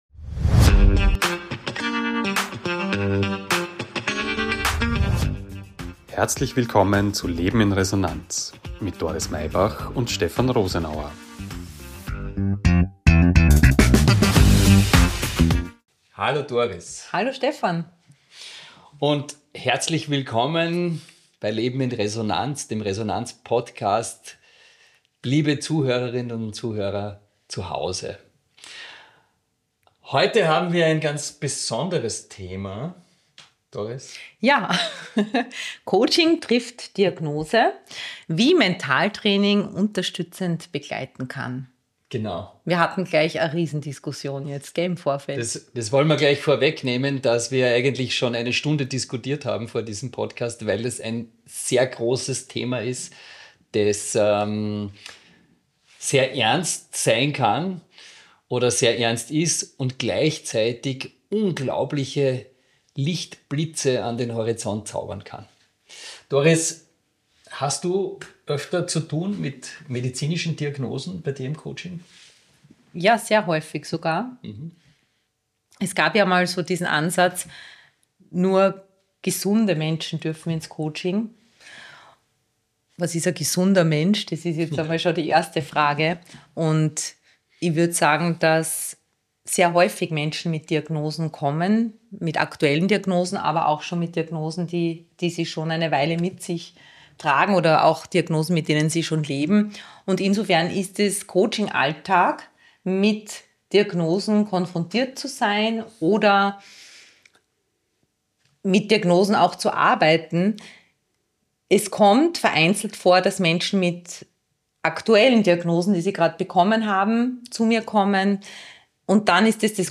Ein Gespräch über Bewusstsein, Stress – und die Kraft der Veränderung.